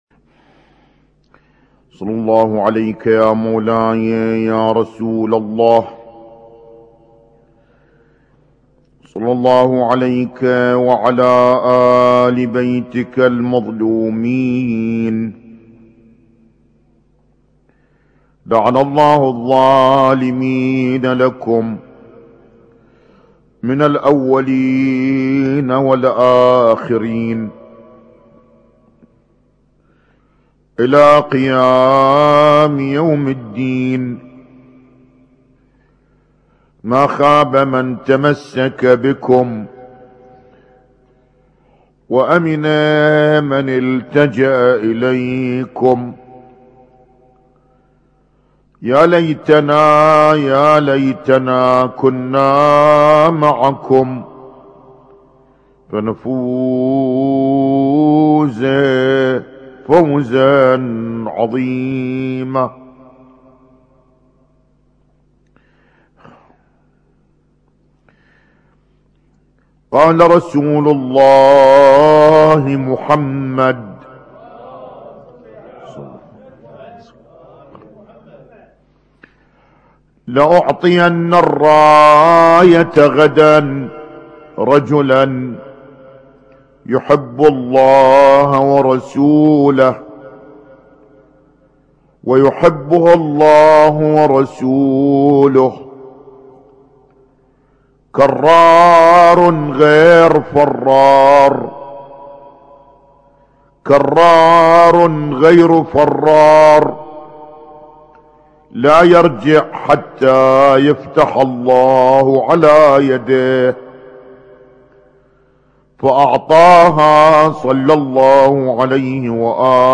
اسم التصنيف: المـكتبة الصــوتيه >> المحاضرات >> المحاضرات الاسبوعية ما قبل 1432